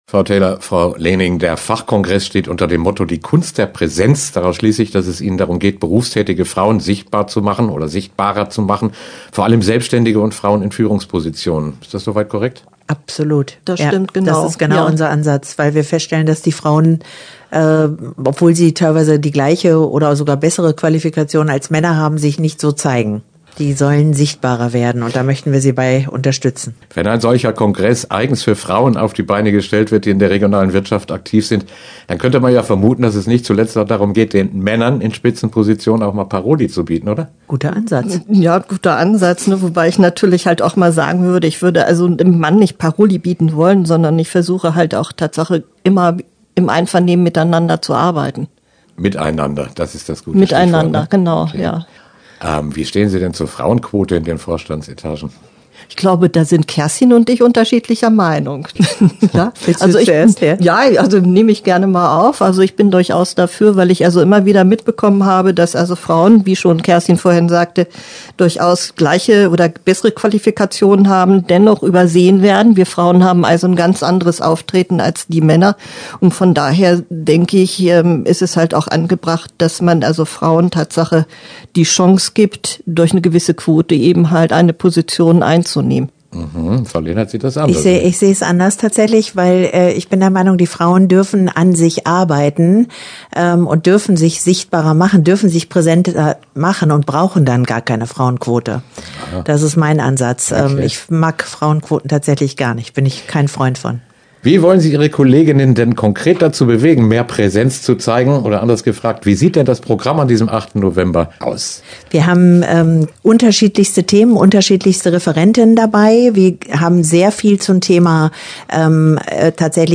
Interview-Wirtschaftsfrauen.mp3